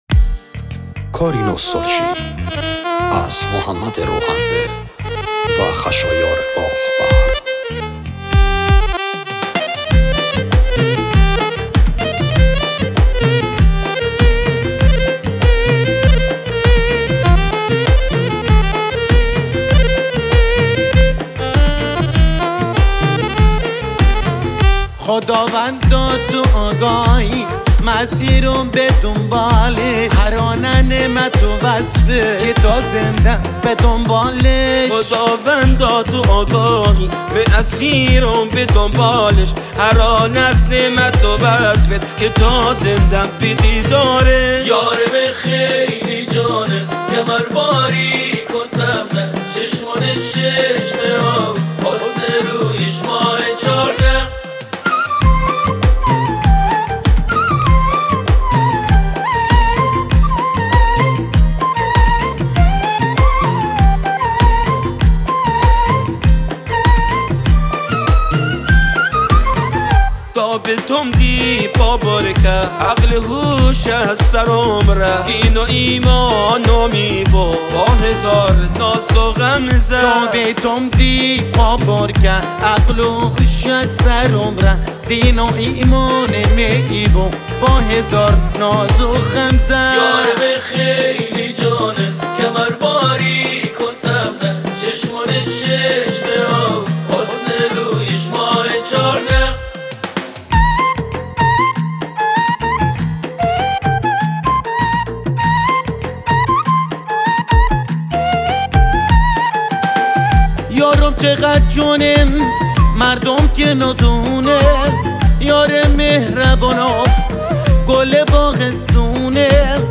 بستکی